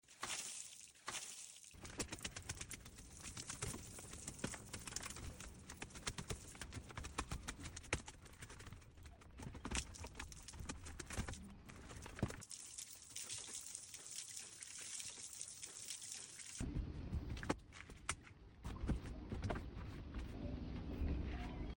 Speed Cleaning/Mopping My Front Door* sound effects free download